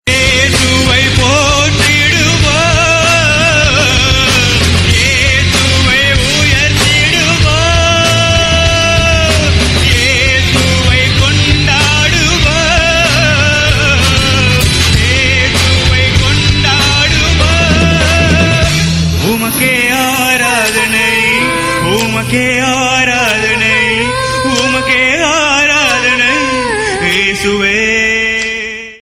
Christian & Gospel RingTones